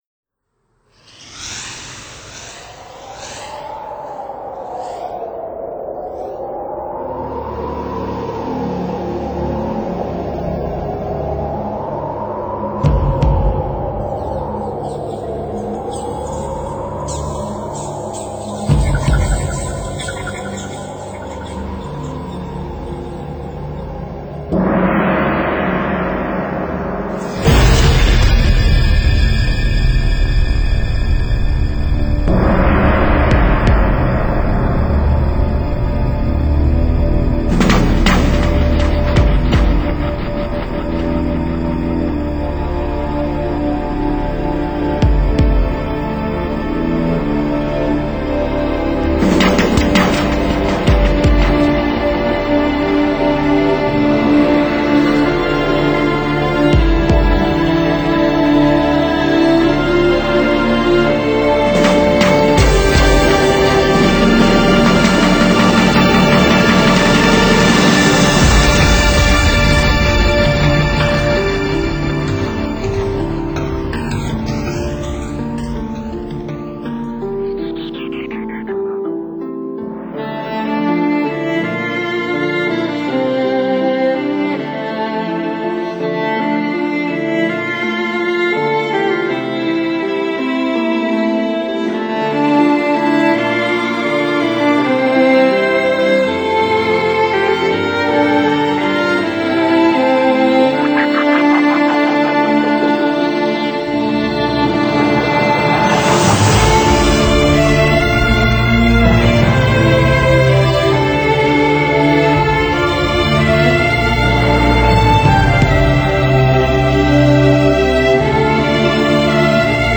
类别：古典改编
而且外形也挺俊朗潇洒，音乐风格趋于古典改编、电子融合，显而易见，
沉重的配器，营造出一种压抑，低沉的气氛，而且旋律的节奏变换比较明显。